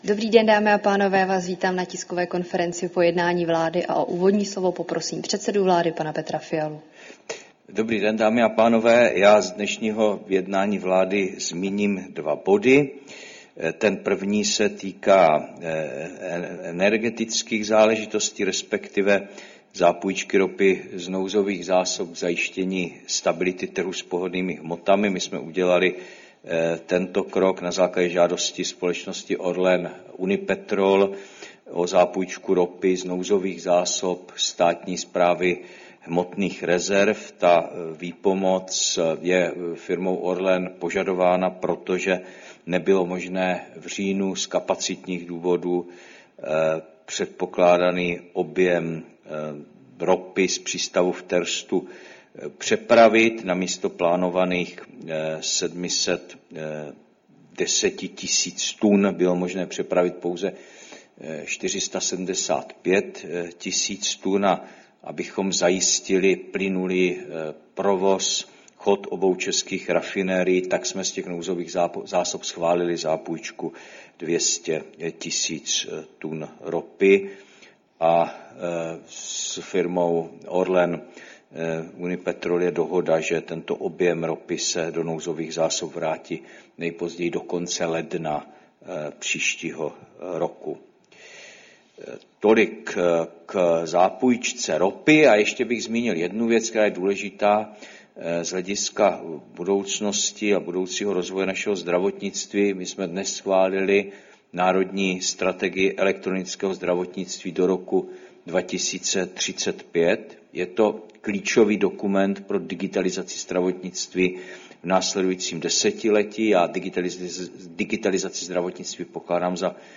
Tisková konference po jednání vlády, 8. října 2025